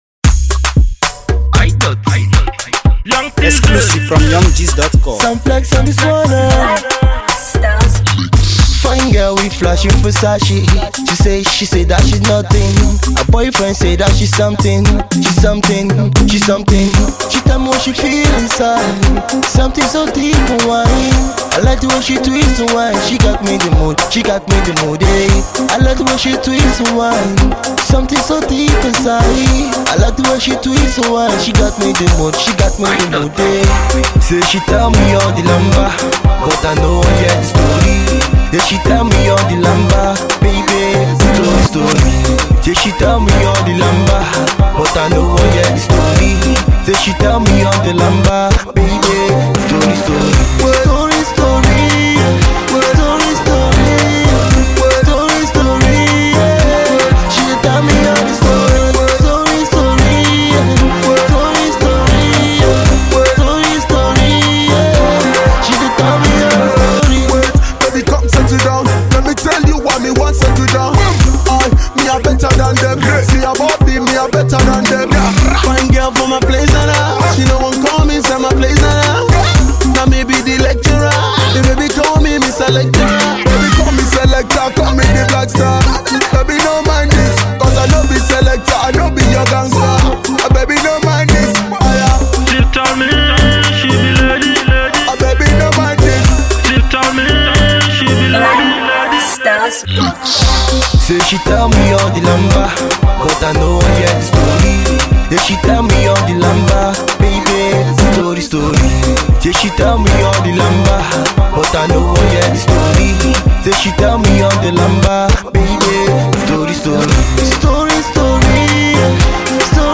It’s unique and has a feel of freshness to it.